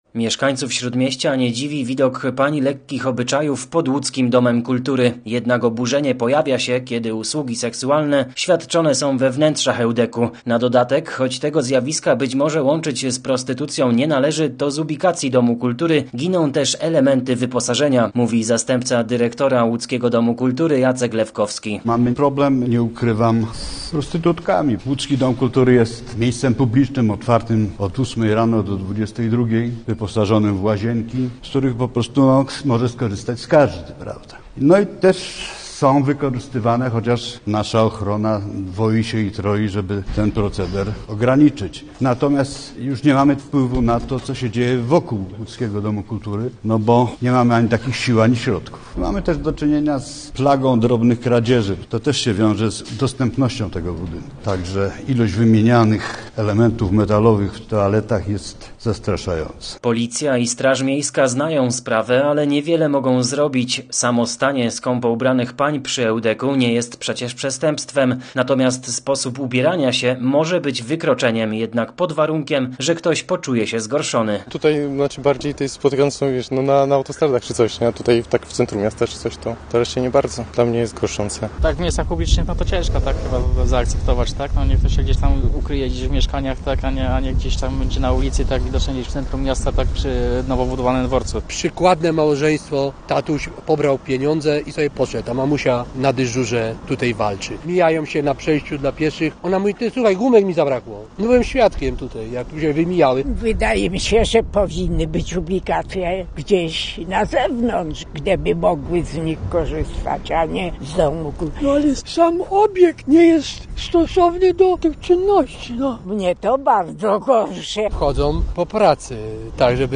Posłuchaj materiału naszego reportera i dowiedz się więcej: Nazwa Plik Autor Problem z prostytucją w ŁDK-u audio (m4a) audio (oga) ZDJĘCIA, NAGRANIA WIDEO, WIĘCEJ INFORMACJI Z ŁODZI I REGIONU ZNAJDZIESZ W DZIALE “WIADOMOŚCI”.